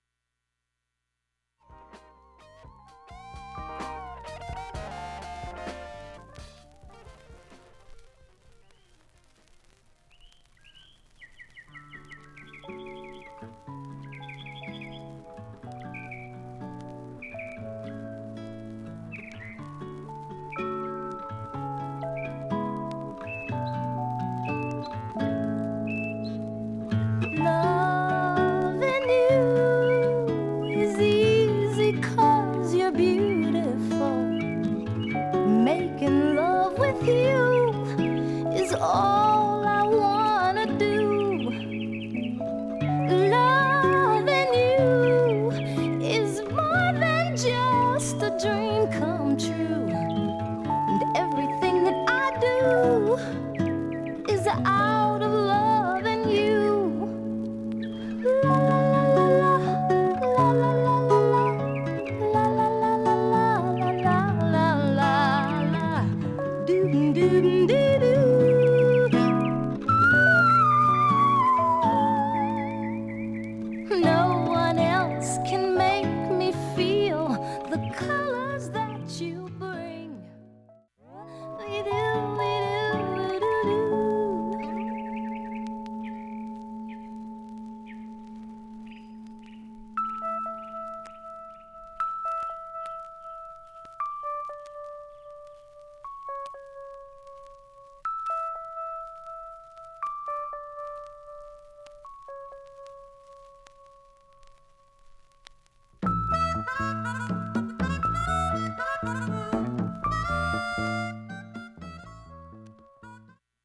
ほか５回までのかすかなプツが１箇所
３回までのかすかなプツが２箇所
単発のかすかなプツが４箇所